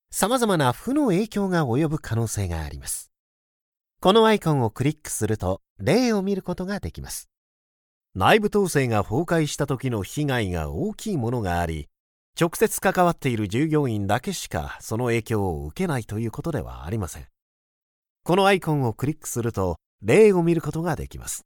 Japanese, Male, Home Studio, 20s-40s
Home Studio Read